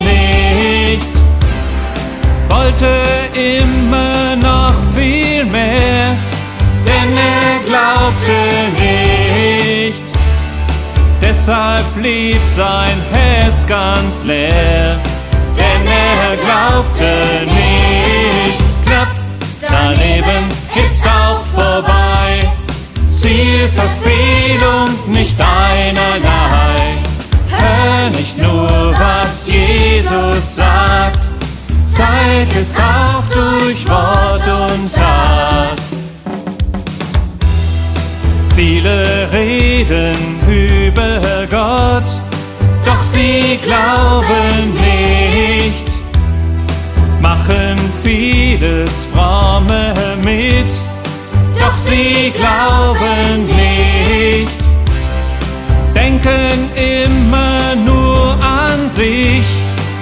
13 neue Lieder für Kids und Teens
Kinderlieder